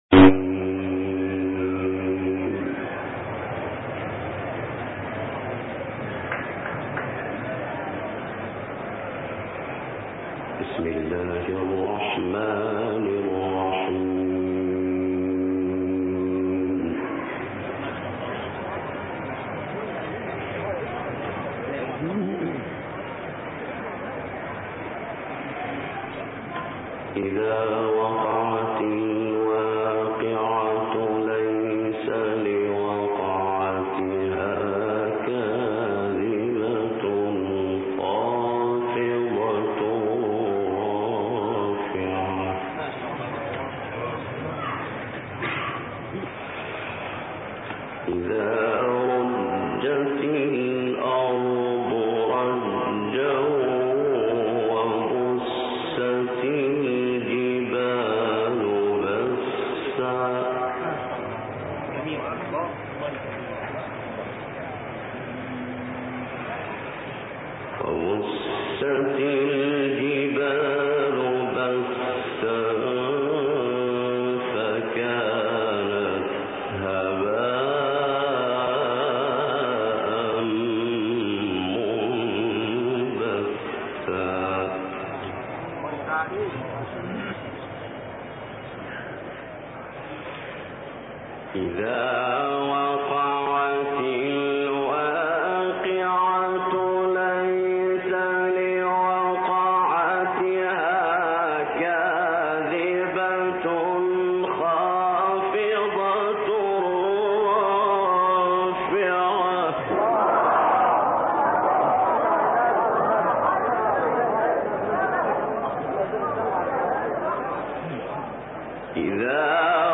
تلاوت سوره واقعه از «محمود صعیدی»
گروه شبکه اجتماعی: تلاوت آیاتی از سوره واقعه با صدای محمود ابوالوفا صعیدی را می‌شنوید.
به گزارش خبرگزاری بین المللی قرآن(ایکنا) تلاوت آیات 1 تا 38 سوره واقعه از محمود ابوالوفا صعیدی، قاری مصری ارائه می‌شود.